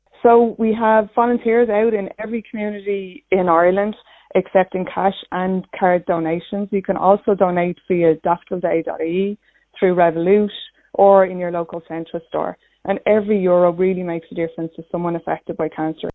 Irish Cancer Society CEO Averil Power says there are plenty ways to donate: